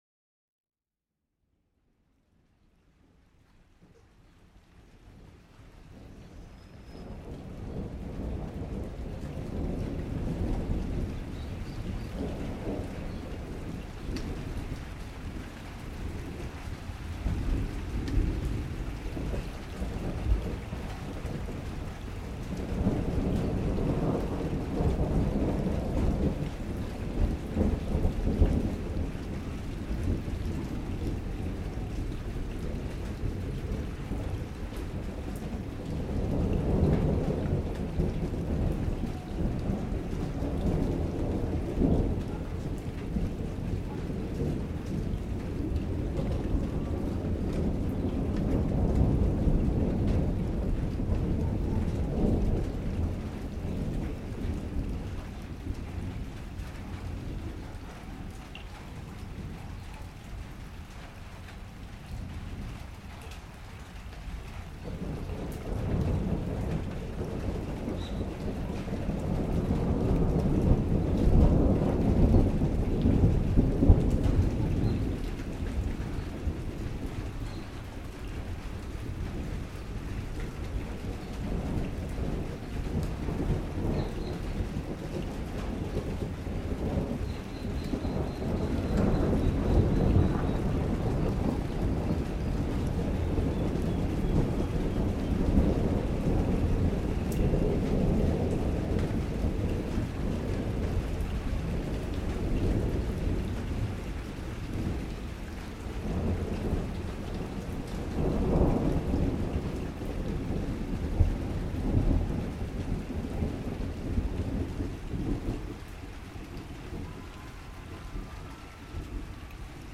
1 The Sound of 2024 44:52 Play Pause 7h ago 44:52 Play Pause Redă mai târziu Redă mai târziu Liste Like Plăcut 44:52 A slow weave of some of the past year’s Field Recordings, from a thunderstorm in Buenos Aires to whistling wind rattling the windows of a lighthouse in the Outer Hebrides. Summer storm, Vedia, Buenos Aires, Argentina in January 2024